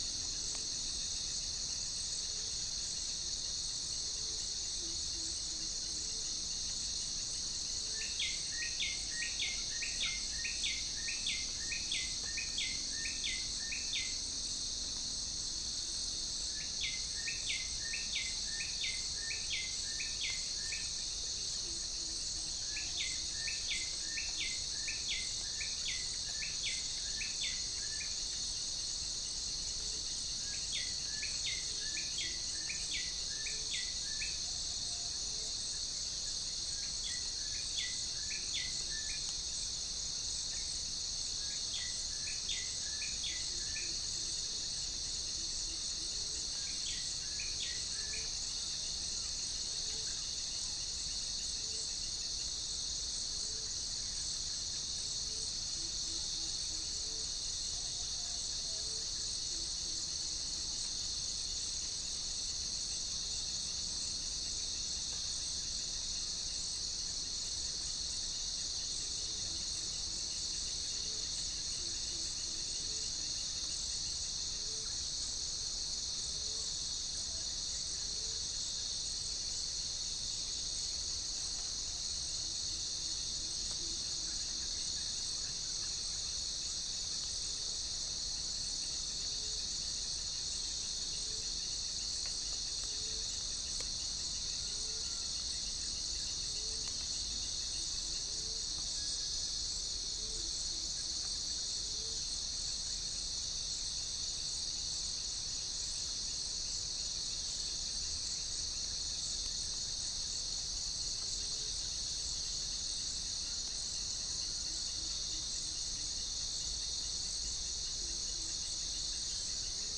Chalcophaps indica
Gallus gallus
Centropus bengalensis
Pycnonotus goiavier
Orthotomus sericeus
Cinnyris jugularis